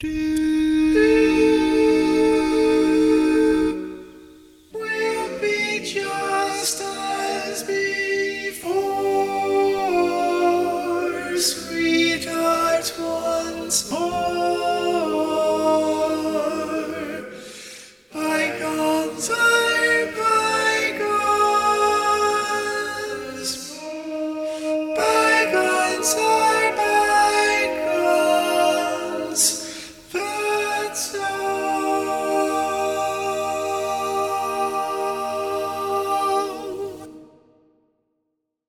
Key written in: E♭ Major
Type: Barbershop